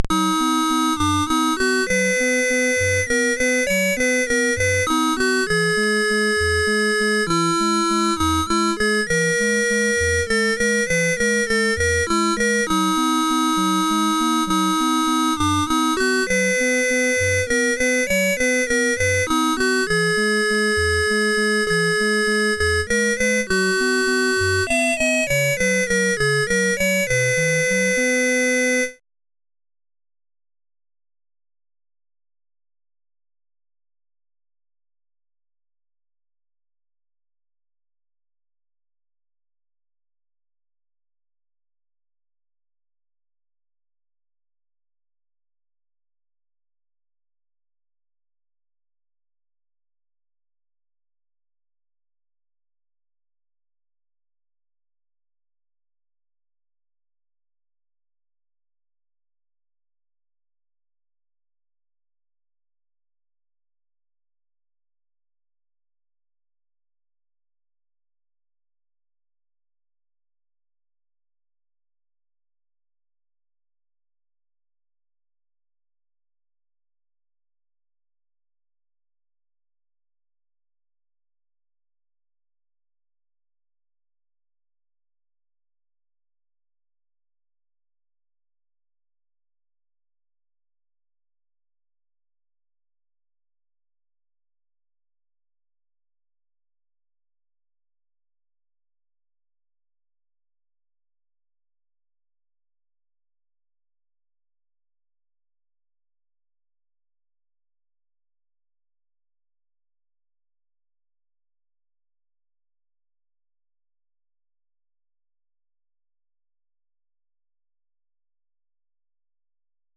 Commodore SID Music File
1 channel
calliope 1.mp3